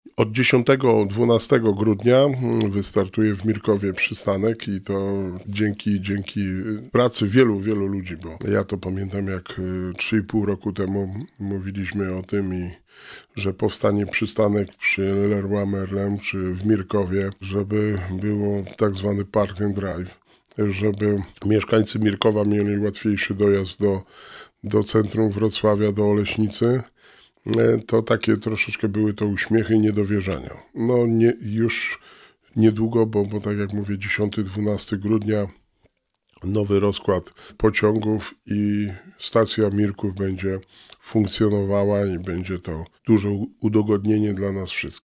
W studiu Radia Rodzina gościł Wojciech Błoński, wójt Gminy Długołęka.